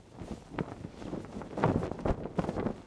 cloth_sail9.R.wav